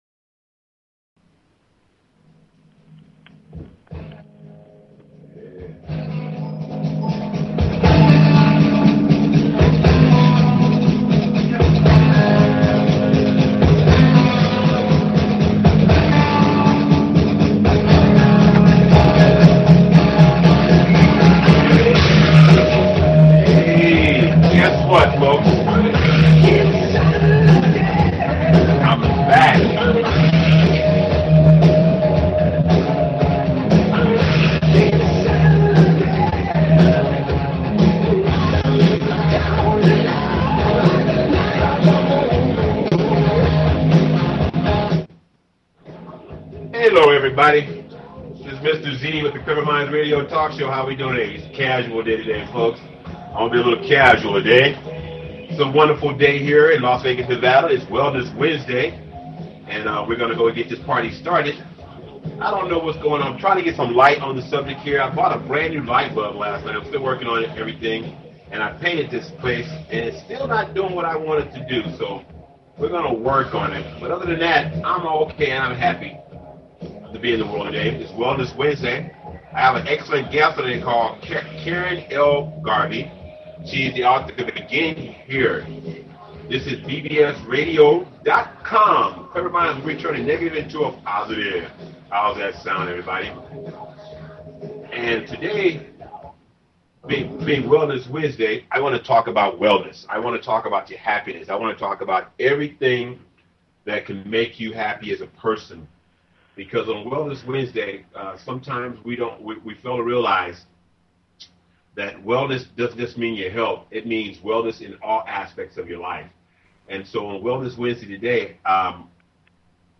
Talk Show Episode, Audio Podcast, Cleverminds and Courtesy of BBS Radio on , show guests , about , categorized as